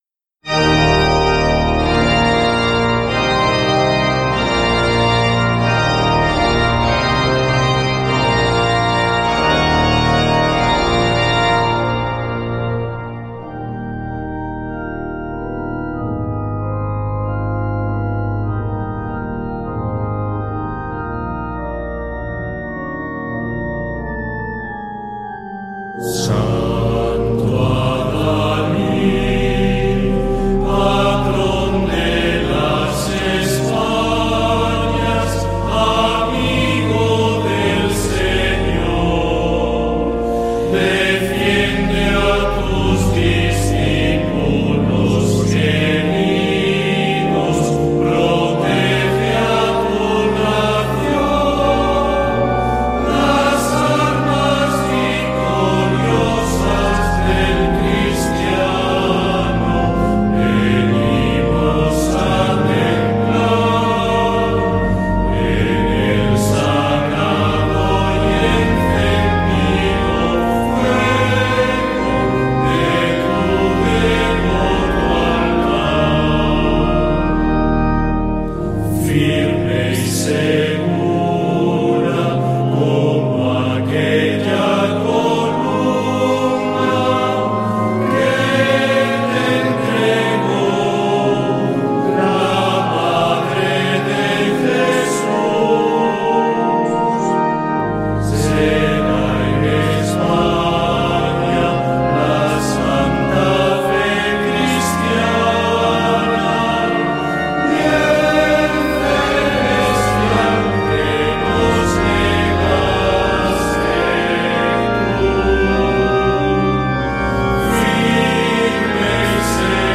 Himno-al-Apostol-Santiago.mp3